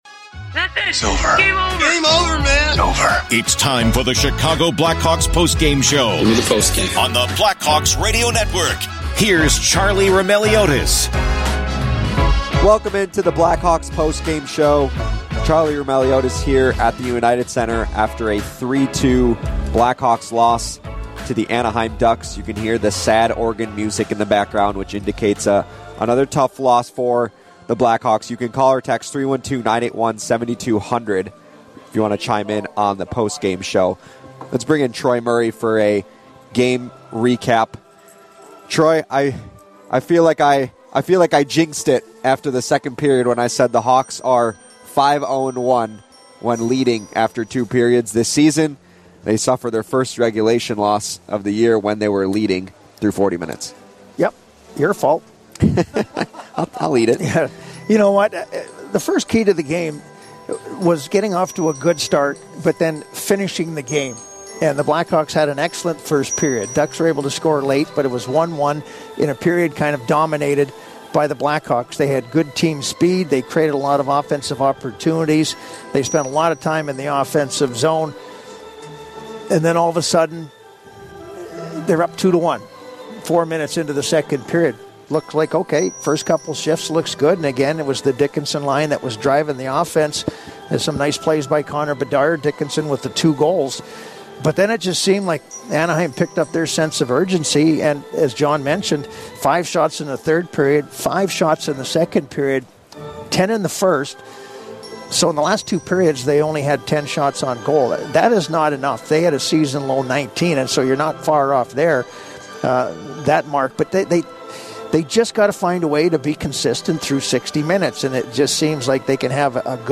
Troy Murray joins the discussion to talk about the Hawks playing well offensively but lacking consistency, and some difficulty with the lines.
Later in the show, we hear audio from Connor Bedard, Jason Dickinson and head coach Luke Richardson, who share their thoughts on the loss.